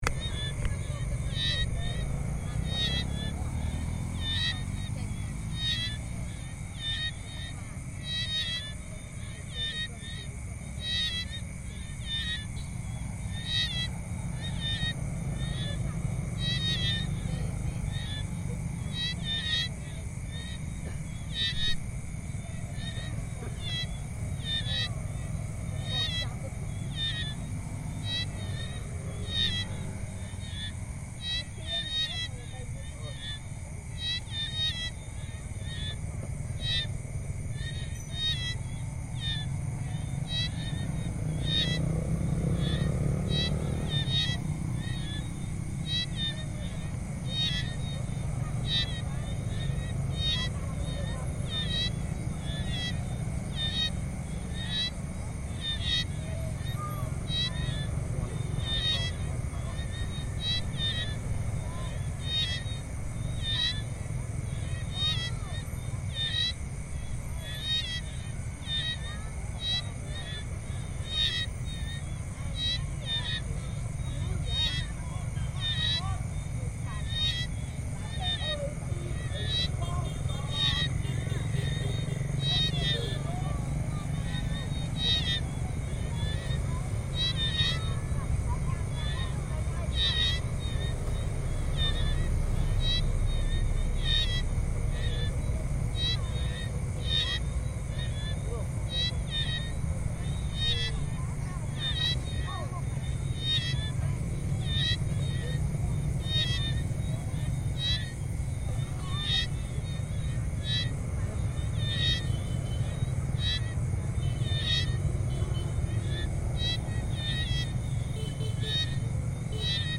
Unidentified creature sounds, Cambodia
What are these strange crepuscular creatures whose calls we can hear in this field recording from Cambodia?